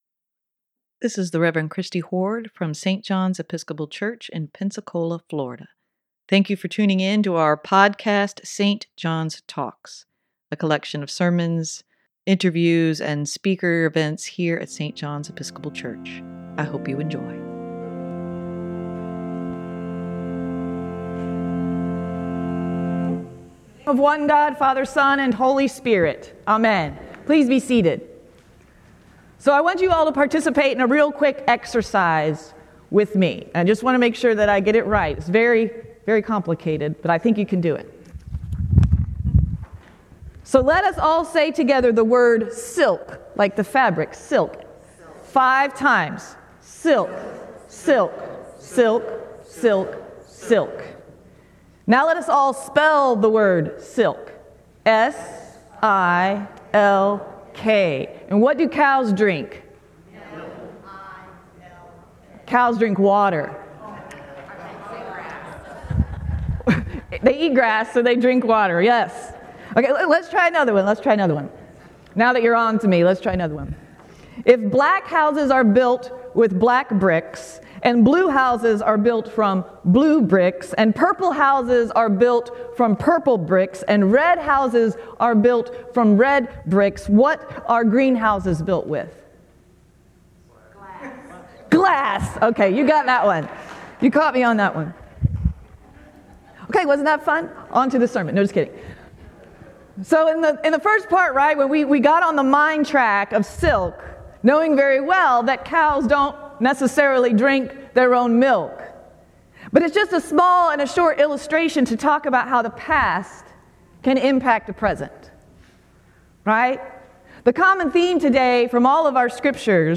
Sermon
sermon-9-17-23.mp3